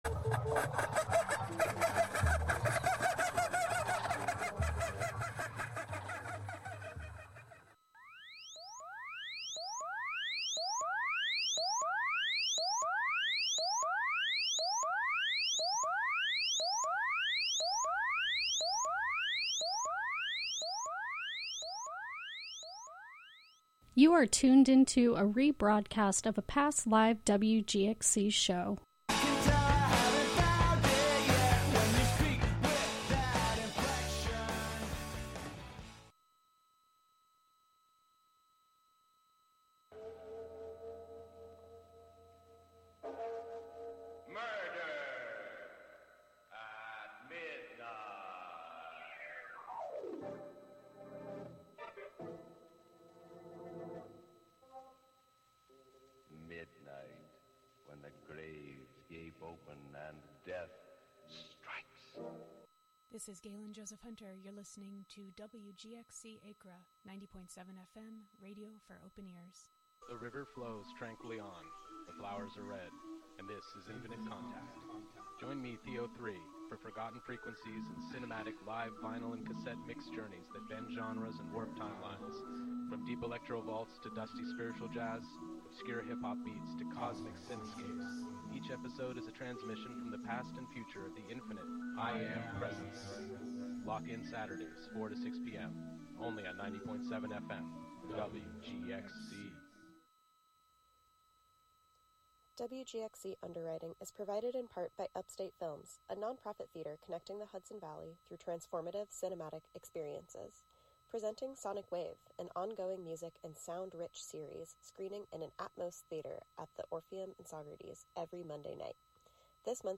A SPLEN-DID hour of sound and music inspired by the recombinant qualities of food with occasional conversations about milk.